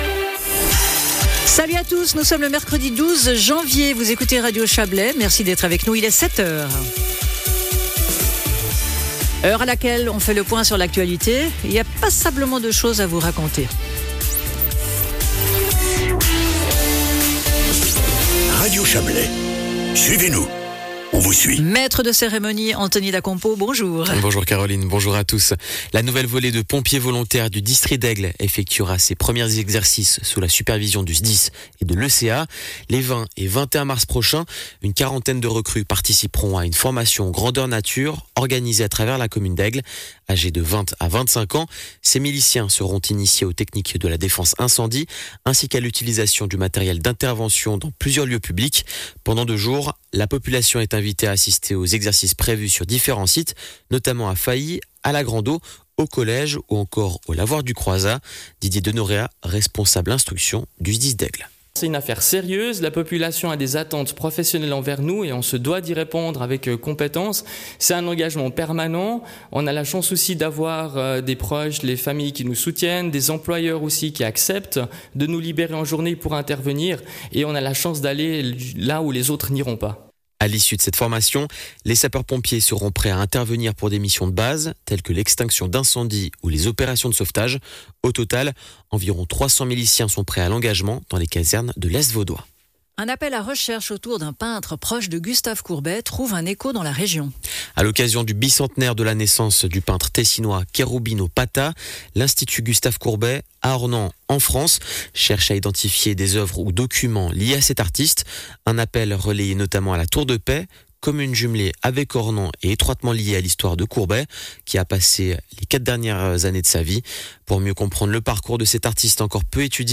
Le journal de 7h00 du 12.03.2026